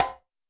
BAL Conga.wav